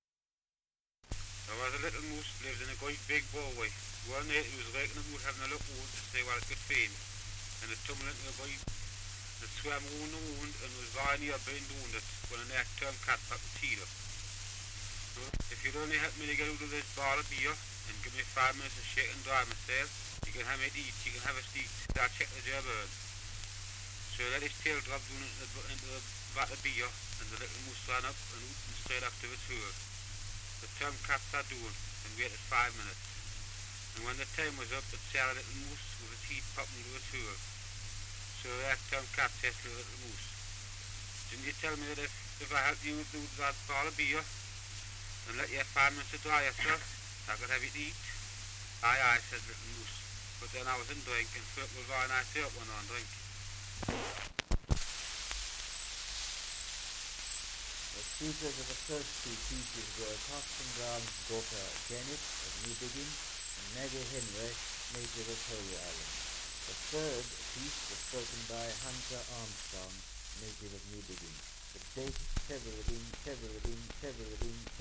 3 - Dialect recording in Newbiggin-by-the-Sea, Northumberland
78 r.p.m., cellulose nitrate on aluminium